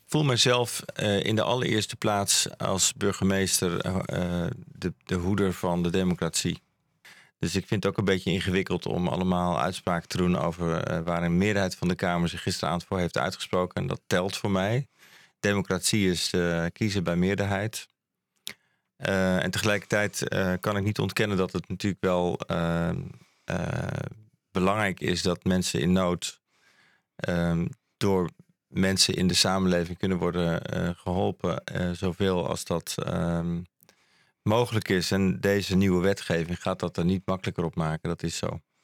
Teaser van het interview